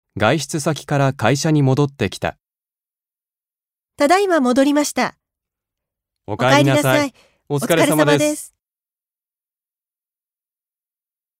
1.1. 会話（社内での日常の挨拶）